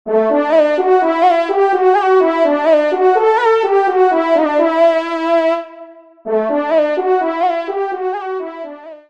Genre :  Divertissement pour Trompes ou Cors
Trompe Chant solo